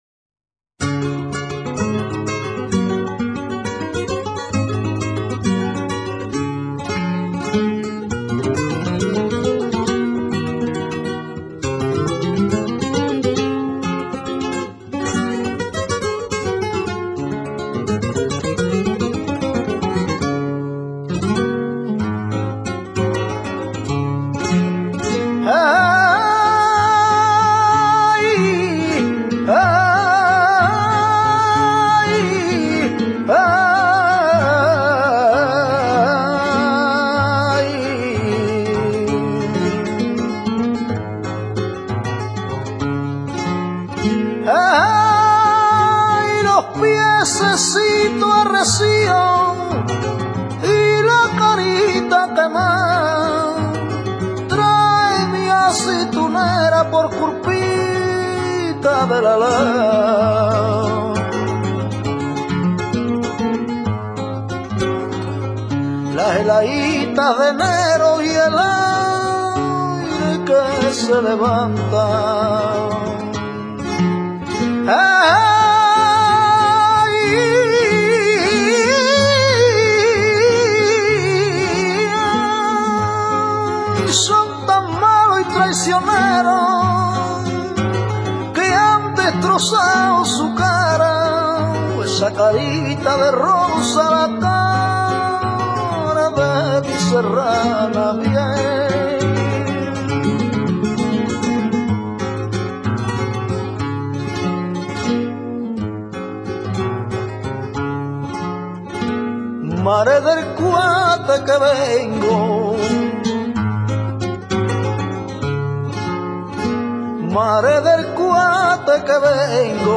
GUAJIRA. f. [De guajiro, canci�n popular usual entre los campesinos de Cuba, y �ste de guajiro, campesinos de Cuba, y �ste de guajiro, campesino blanco de Cuba, se�or] Cante aflamencado procedente del folklore cubano, con copla de diez versos octos�labos o d�cima. Sus letras se refieren, fundamentalmente, a La Habana y sus habitantes, o a temas de amor y nostalgia.
Posee un ritmo muy conciso por lo que cuando se taconea, el acompa�amiento de guitarra esta desprovisto de falsetas y s�lo se hace con rasgueos.
guajira.mp3